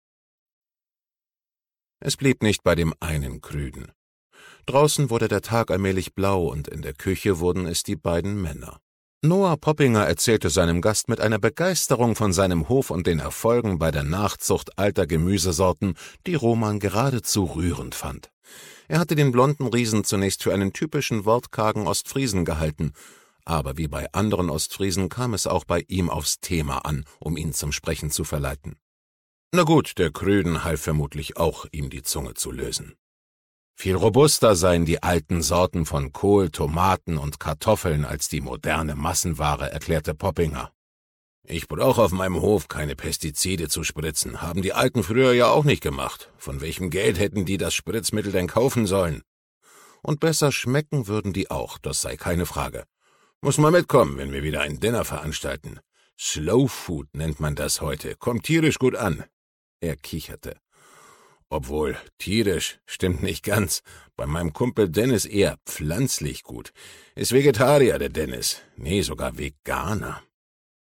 Kulinarischer Kriminalroman